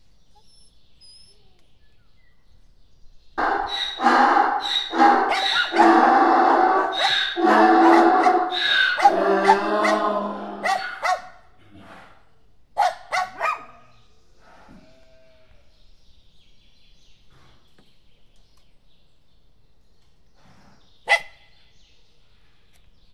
Directory Listing of /_MP3/allathangok/szegedizoo2011_standardt/magyarparlagiszamar/
szamarboges00.23.wav